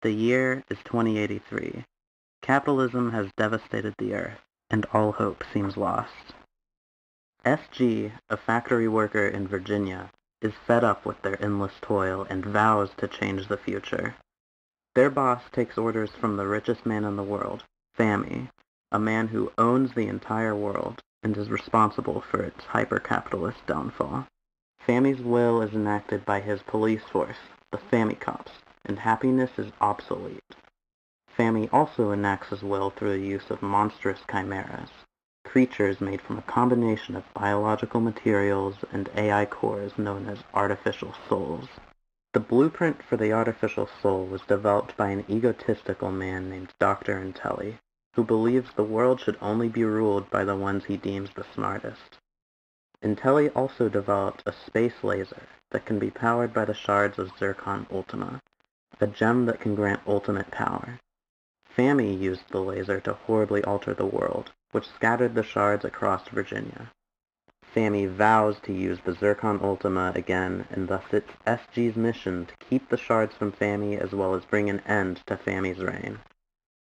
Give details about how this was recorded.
de-essed intro